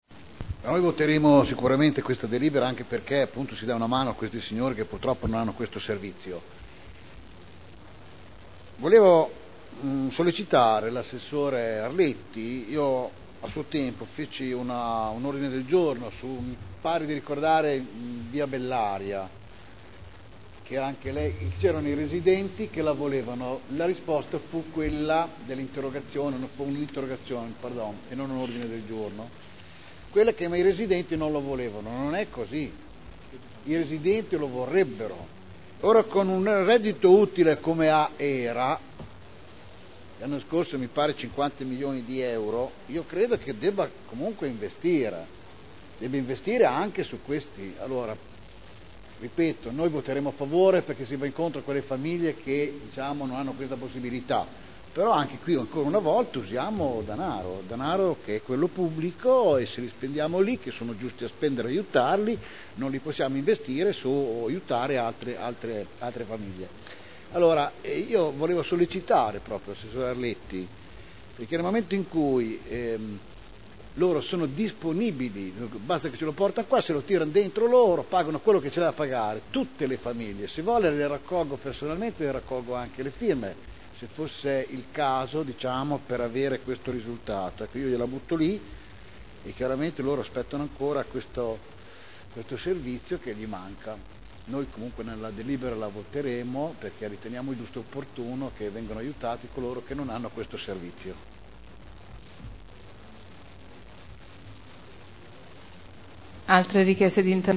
Mauro Manfredini — Sito Audio Consiglio Comunale